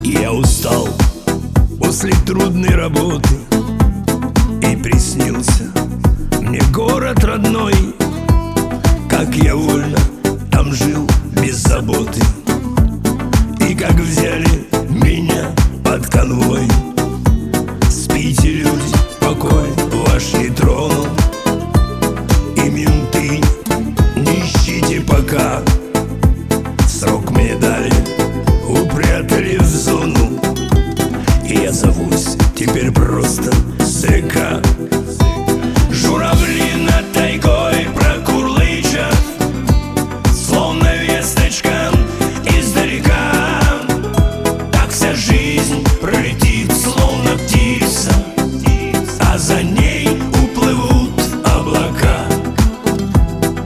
• Качество: 320, Stereo
спокойные
блатные
зоновские